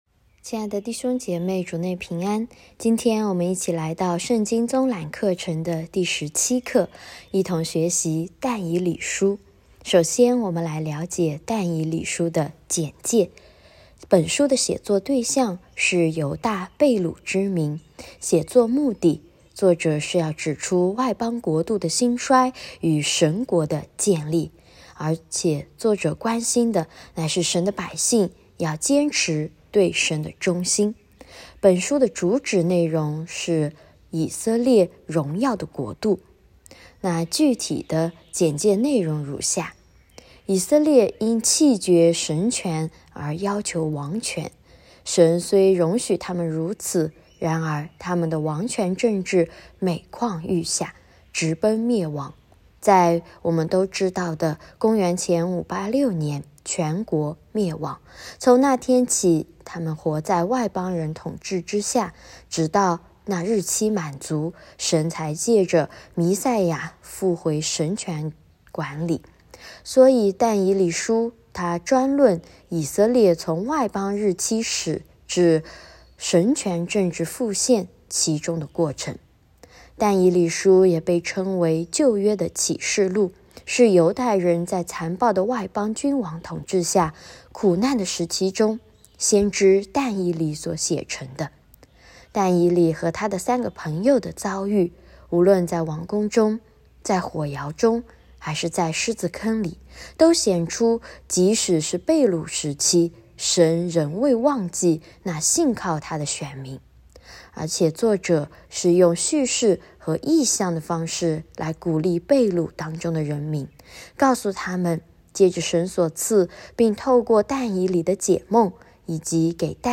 课程音频： /wp-content/uploads/2023/03/圣经综览17.但以理书.m4a 课程讲义：圣经综览17——但以理书 一、《但以理书》简介 对象：犹大被掳之民 目的：指出外邦国度的兴衰与神国的建立，关心神的百姓要坚持对神的忠心。